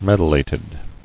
[med·ul·lat·ed]